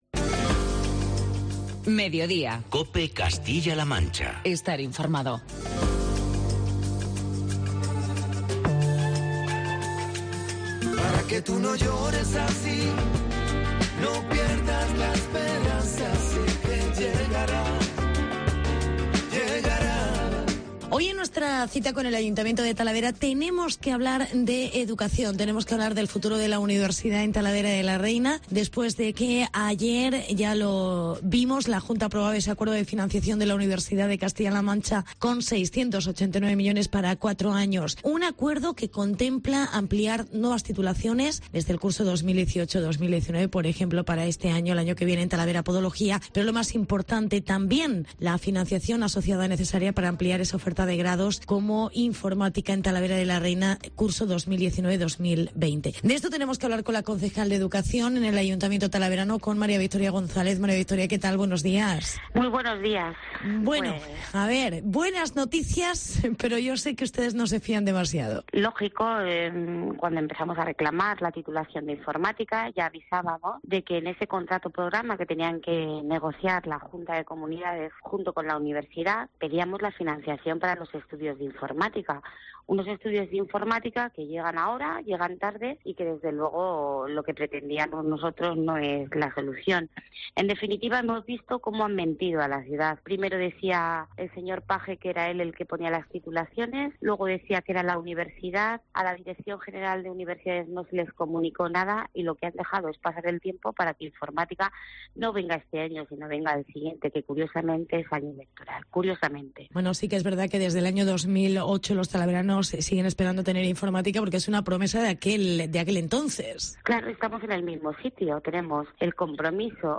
Entrevista con la concejal Mª Victoría González